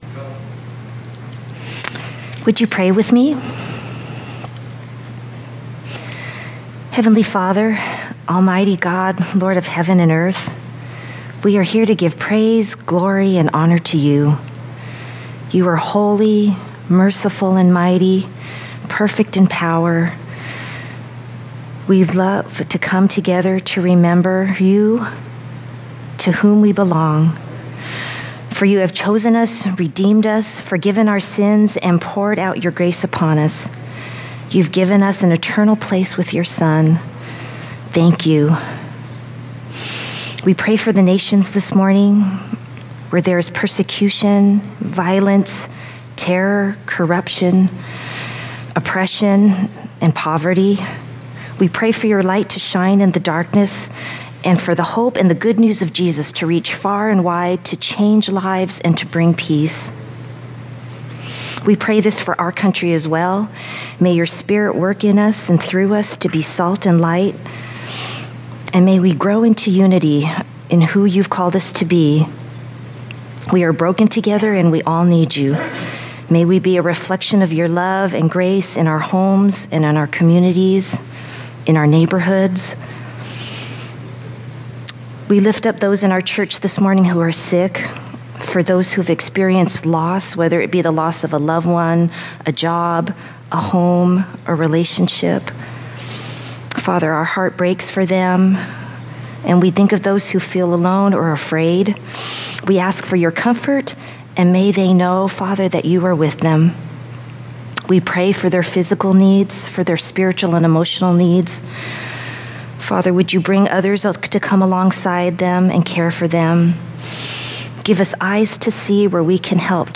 Sermon Questions Audio (MP3) PDF Previous Do You Want to Get Well?